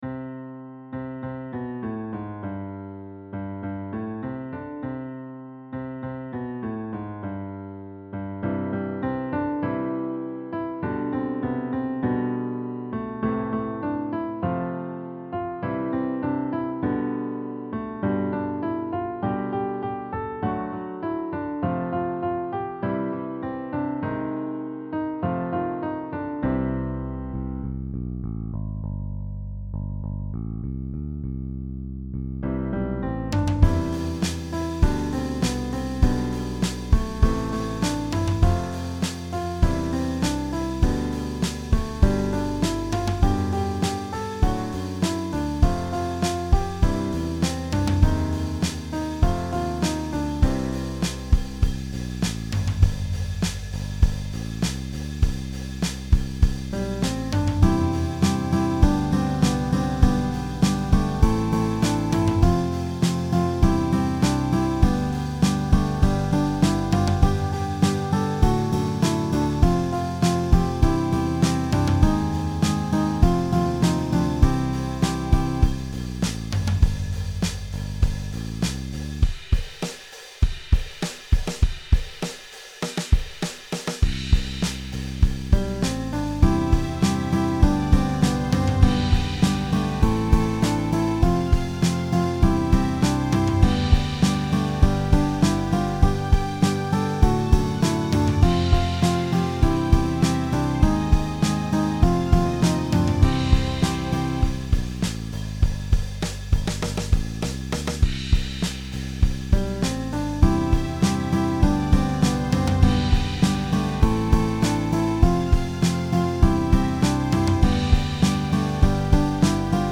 Oriental